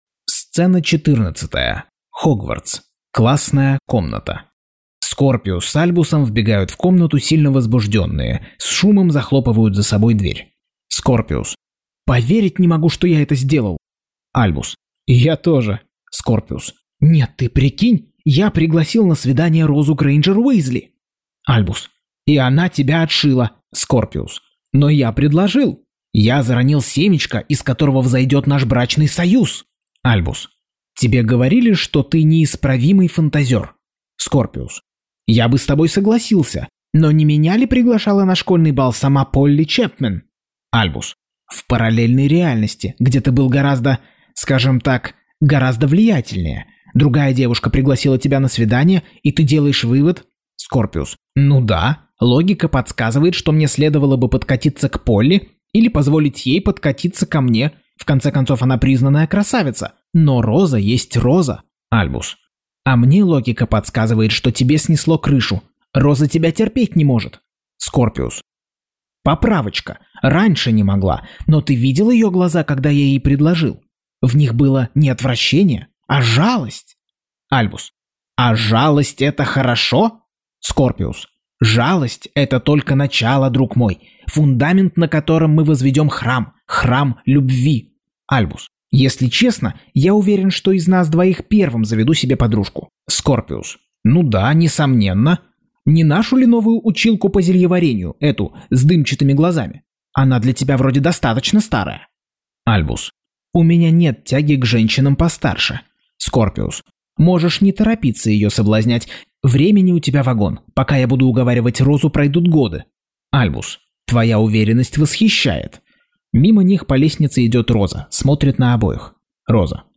Аудиокнига Гарри Поттер и проклятое дитя. Часть 63.